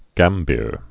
(gămbîr)